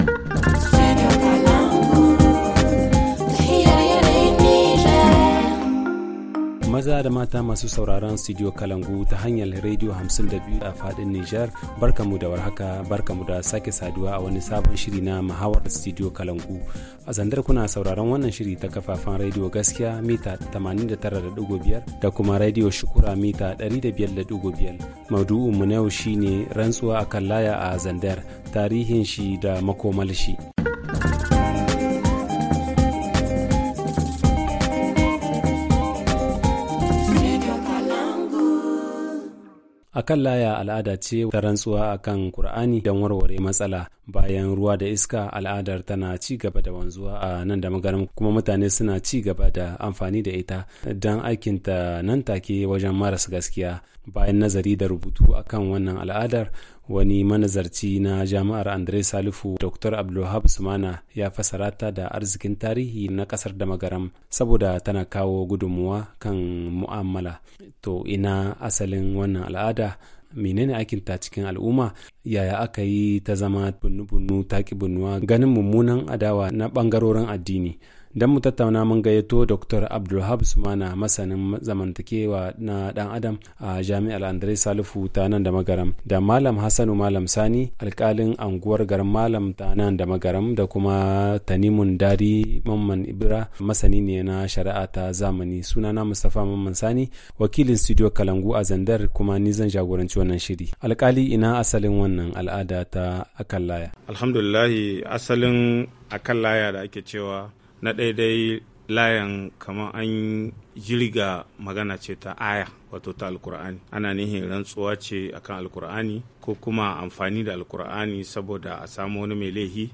Le forum en haoussa